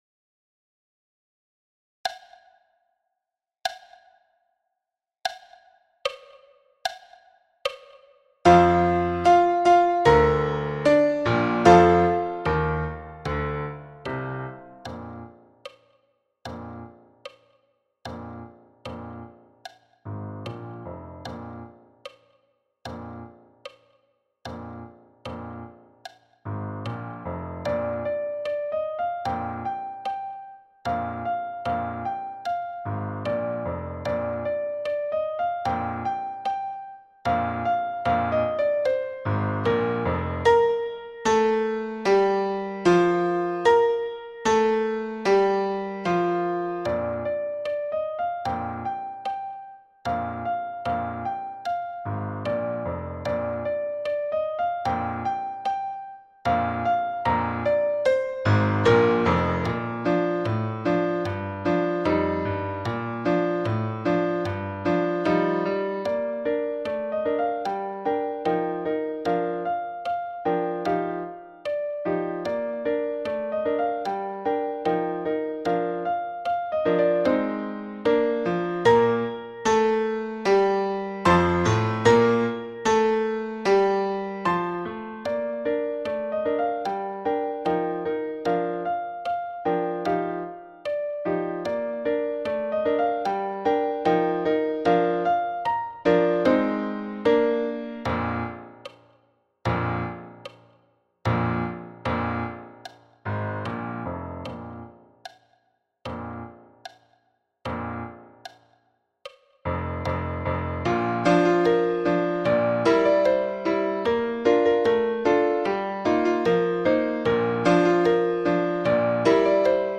Drummed out – piano block à 75 bpm
Drummed-out-piano-block-a-75-bpm.mp3